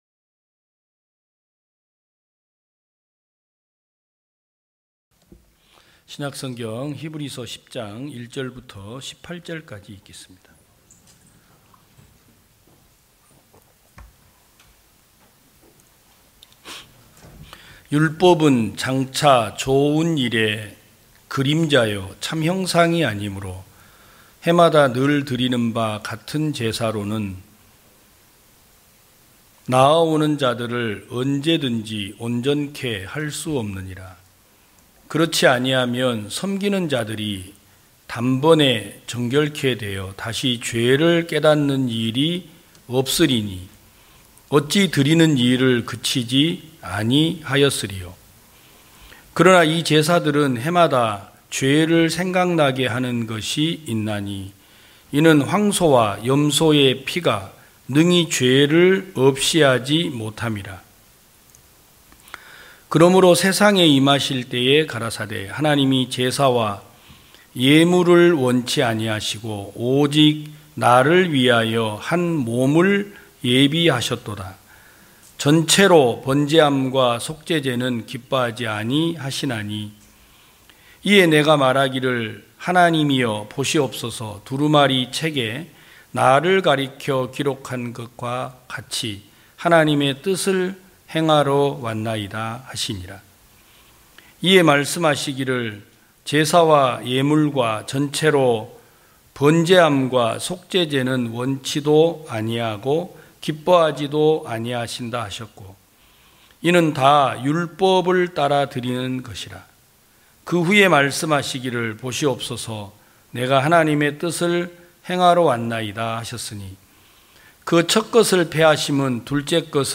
2022년 10월 30일 기쁜소식부산대연교회 주일오전예배
성도들이 모두 교회에 모여 말씀을 듣는 주일 예배의 설교는, 한 주간 우리 마음을 채웠던 생각을 내려두고 하나님의 말씀으로 가득 채우는 시간입니다.